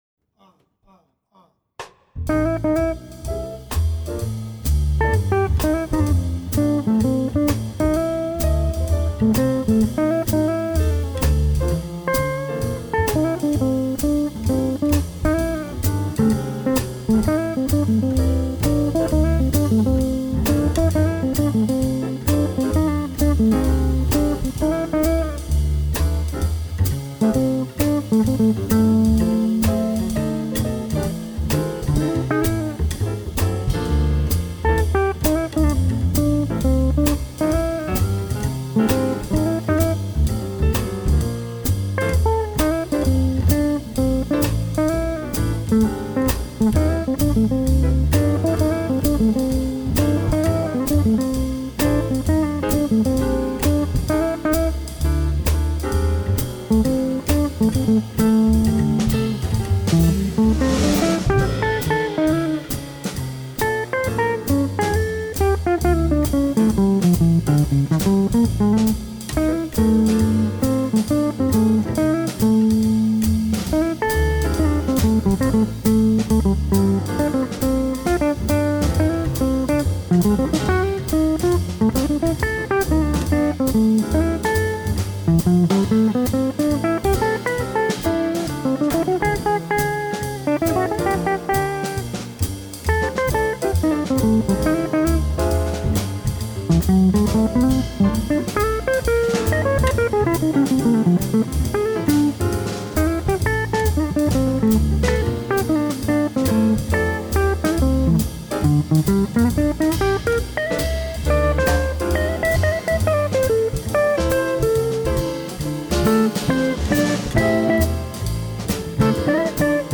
jouée sur Nina double humbucker
guitariste